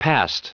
Prononciation du mot past en anglais (fichier audio)
Prononciation du mot : past